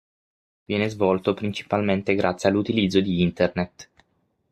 grà‧zie
/ˈɡrat.t͡sje/